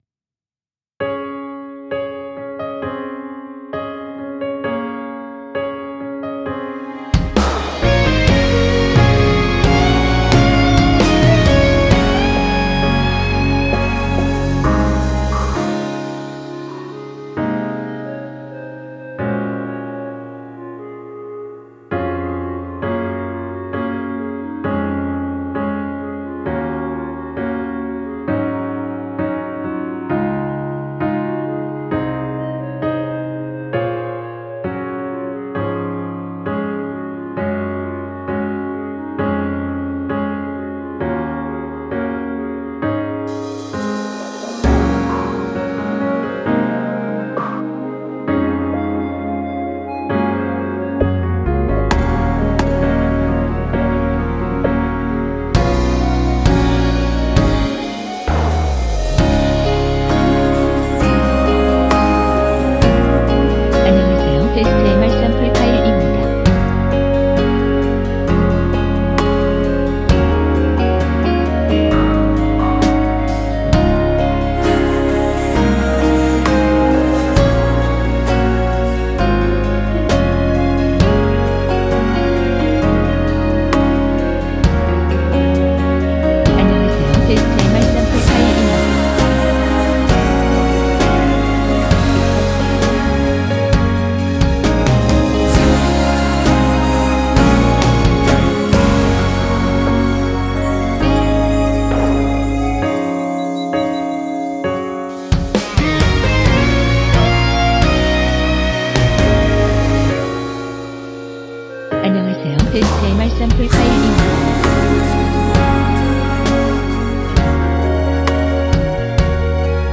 원키 멜로디와 코러스 포함된 MR입니다.(미리듣기 확인)
Db
위처럼 미리듣기를 만들어서 그렇습니다.